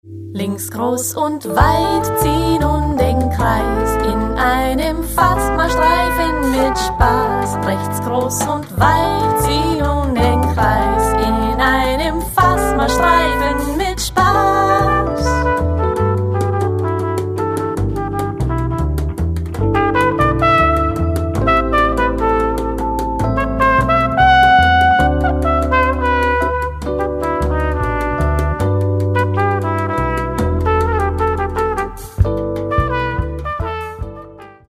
Recorded at: Dschungelstudios Vienna, Austria